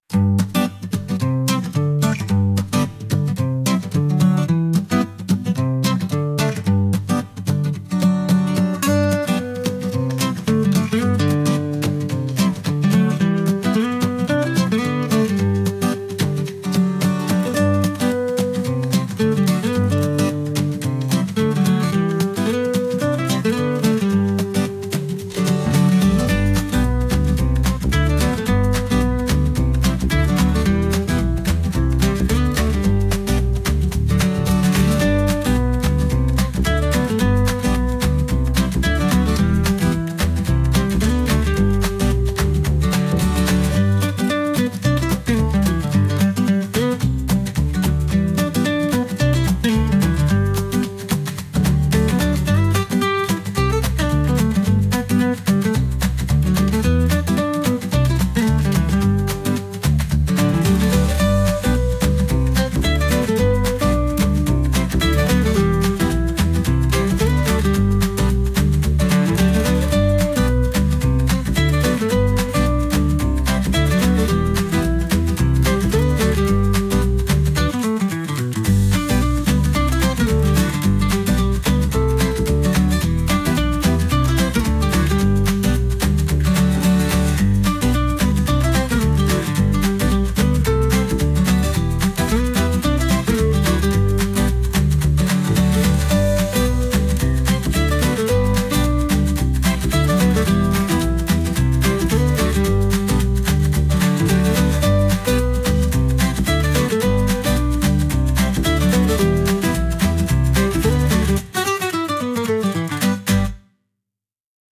A cute Country-style track created specifically for kids.
陽気なカントリーミュージック調の、キッズ選手に特化したとびきり可愛い一曲！
アコースティックギターの音色と、カントリー特有の明るく弾むようなリズムが特徴です。